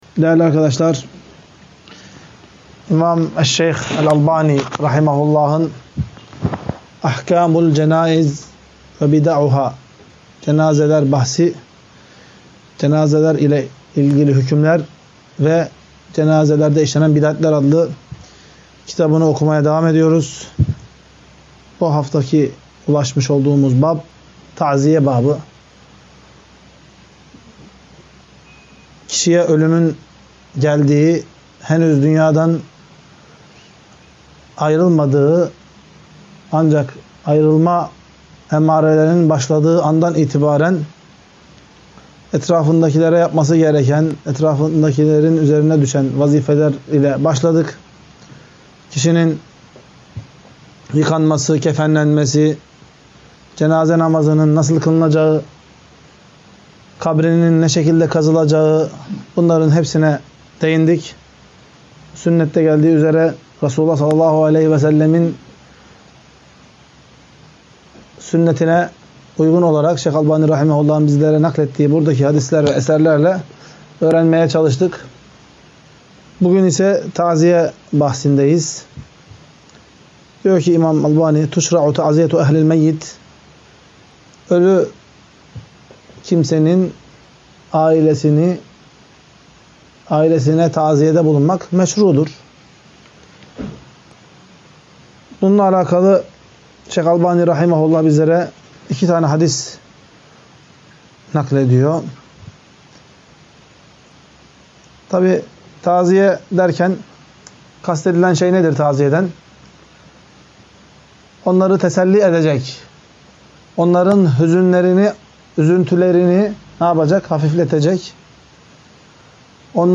20. Ders - CENÂZE AHKÂMI VE CENÂZEDE YAPILAN BİDATLER